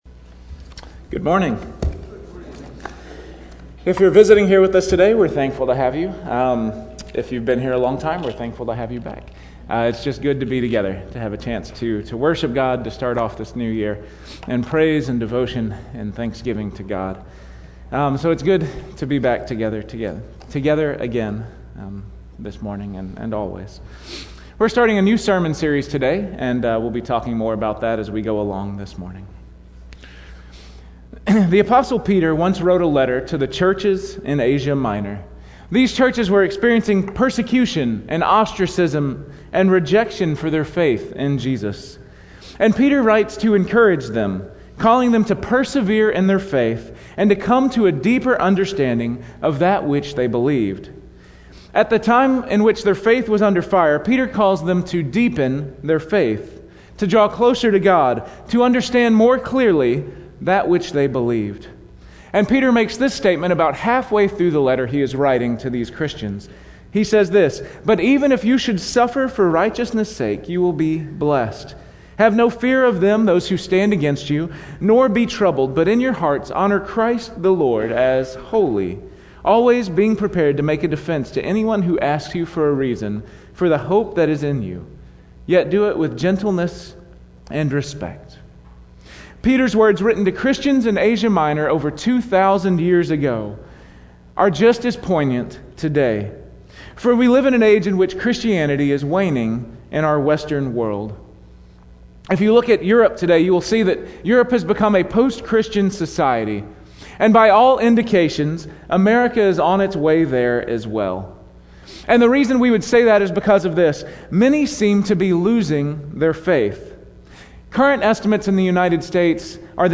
Weekly Sermon Audio “What We Believe About…